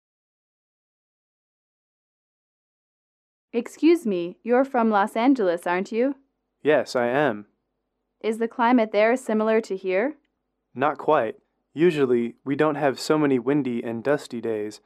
英语主题情景短对话22-1：洛杉矶的气候(MP3)